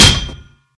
metalwhack.mp3